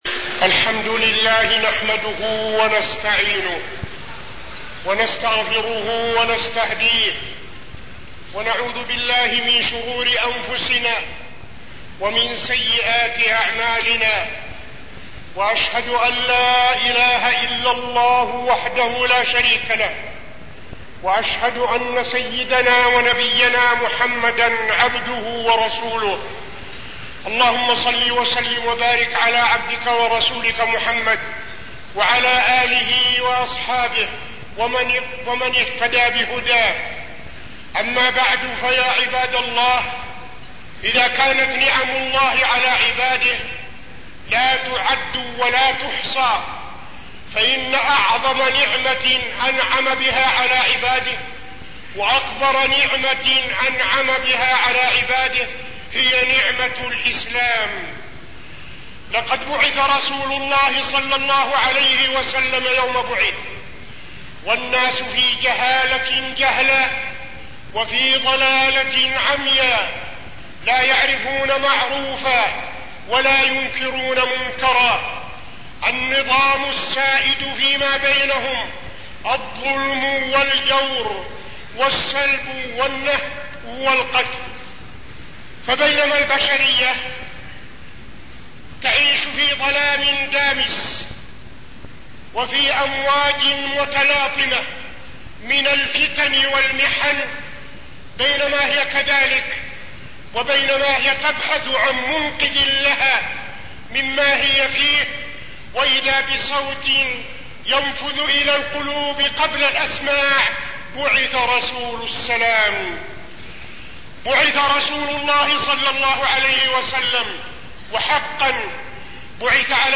تاريخ النشر ٢١ ذو الحجة ١٤٠٢ المكان: المسجد النبوي الشيخ: فضيلة الشيخ عبدالعزيز بن صالح فضيلة الشيخ عبدالعزيز بن صالح نعمة الإسلام